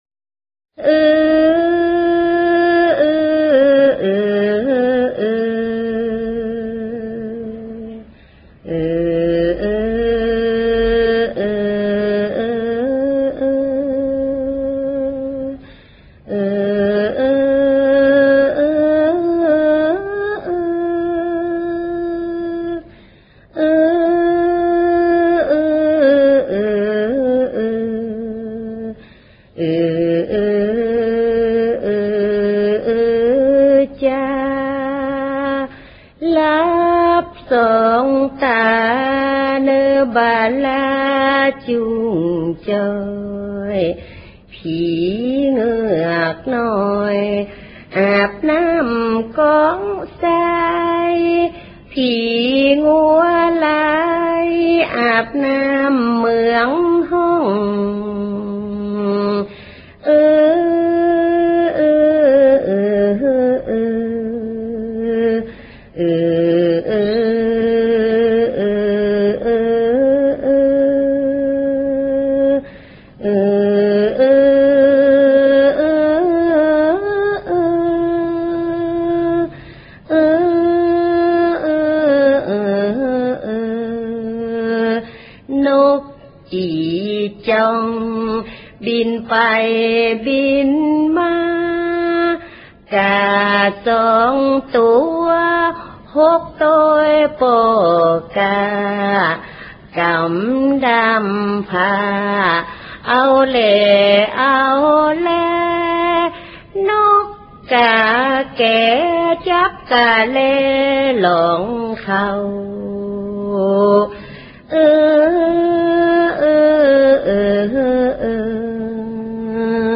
タイの子守唄（古謡）
「ルクトゥン」にしろ「モーラム」にしろ、現在聴くことの出来るタイの民族歌謡というのは、ほとんどが、「西欧の音楽」の洗礼を受けており、タイの「原始の音楽」をしかも「アカペラ」で聴く機会など滅多にないことだと思い、そんな貴重な「音源」を紹介できることを、誇りに思っております。